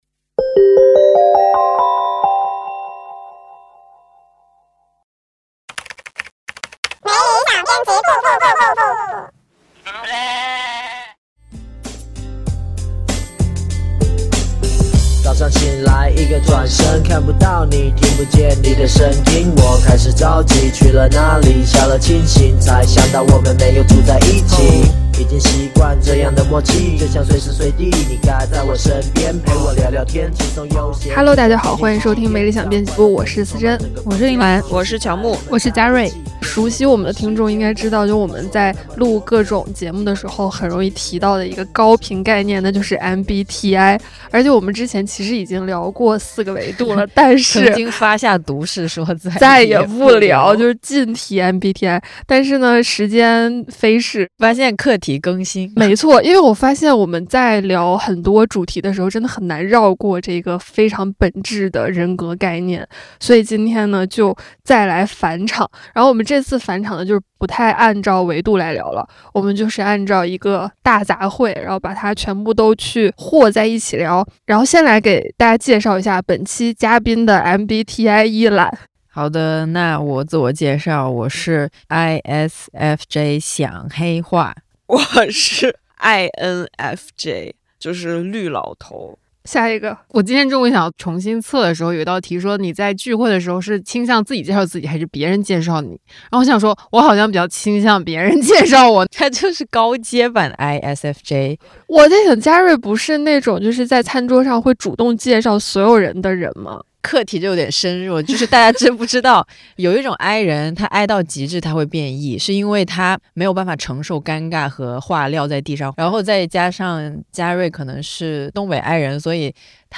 🧁这是我们2024年的最后一期节目，编辑部全员出动，齐聚一堂，回顾这表面平静，底下汹涌的一年。